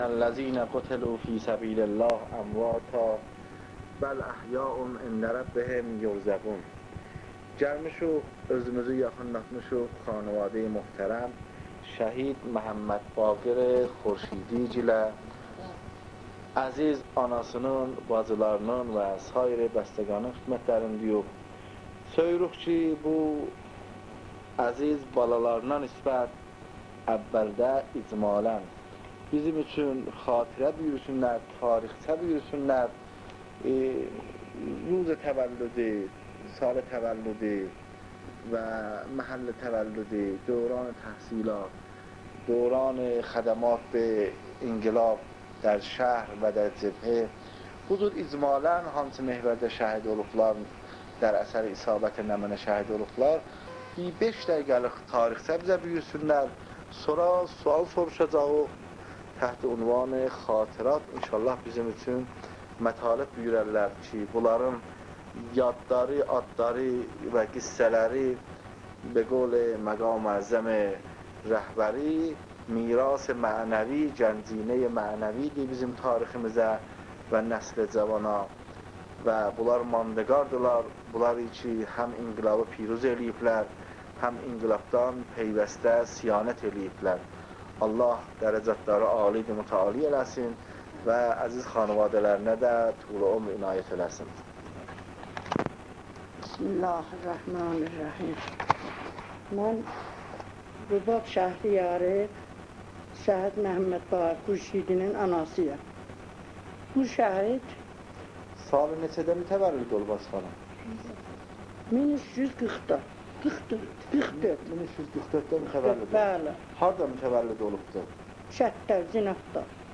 صوت / مصاحبه